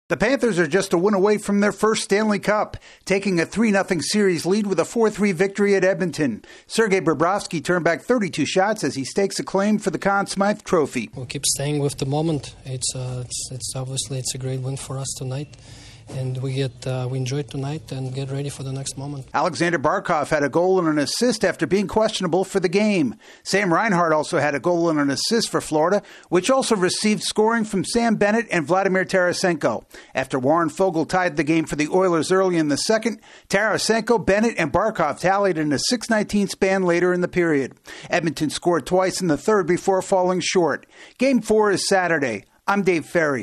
The Panthers are on the verge of bringing a championship to the Miami area. AP correspondent